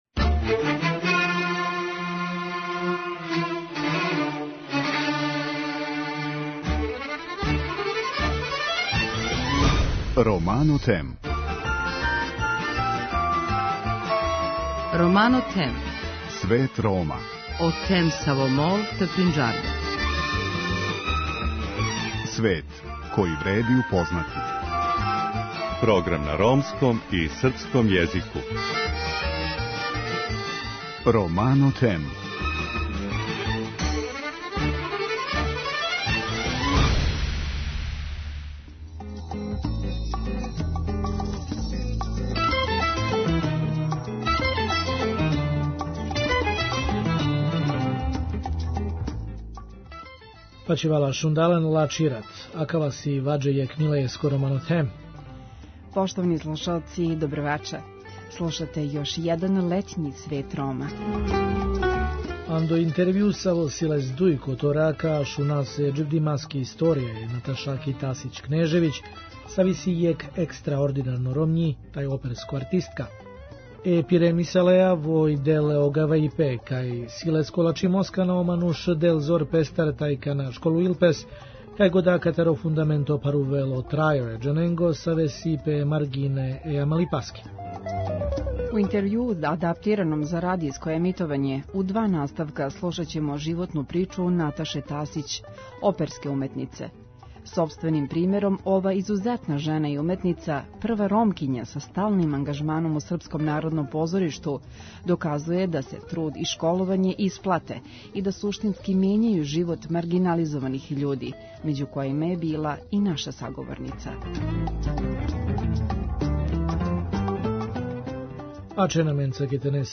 У интервјуу адаптираном за радијско емитовање, слушаћемо њену животну причу у два наставка.